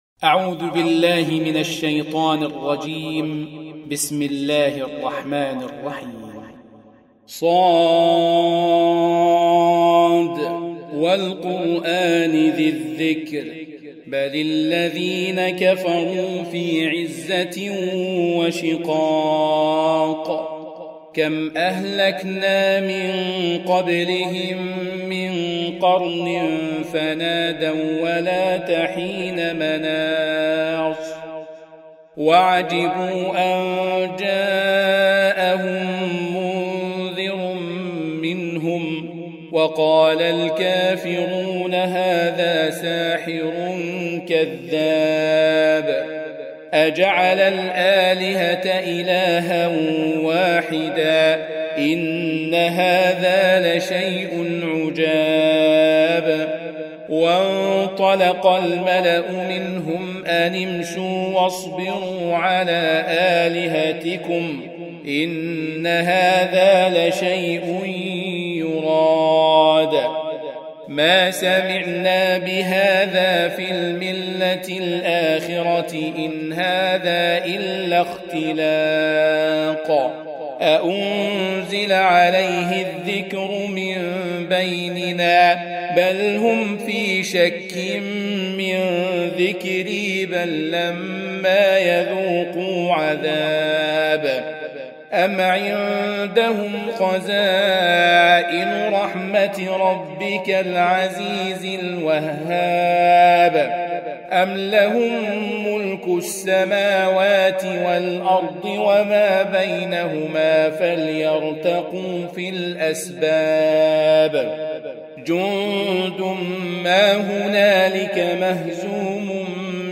38. Surah S�d. سورة ص Audio Quran Tarteel Recitation
Surah Repeating تكرار السورة Download Surah حمّل السورة Reciting Murattalah Audio for 38.